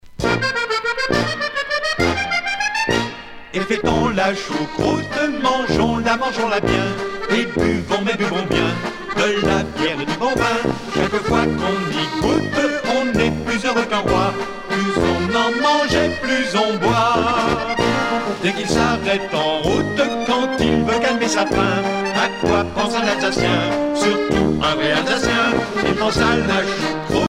valse musette
Pièce musicale éditée